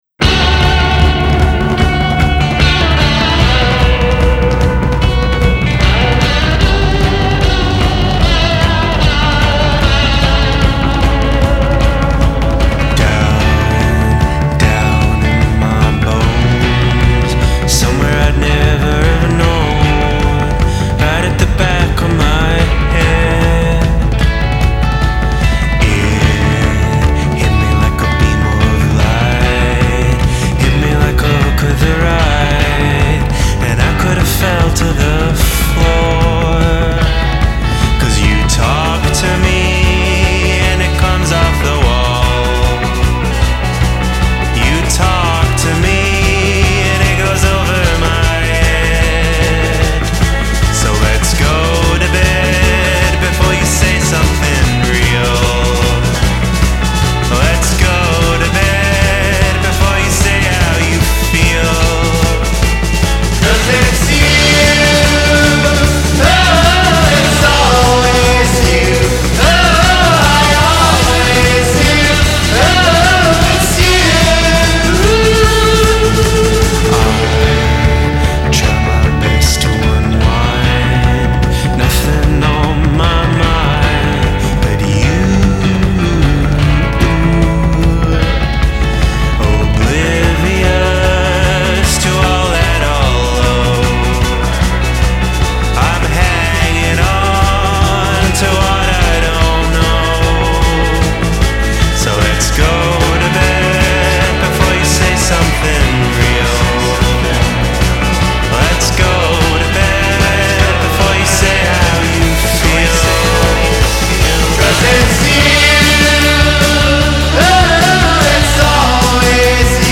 Sus temas son cortos, sencillos, directos y pegajosos.